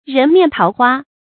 注音：ㄖㄣˊ ㄇㄧㄢˋ ㄊㄠˊ ㄏㄨㄚ
人面桃花的讀法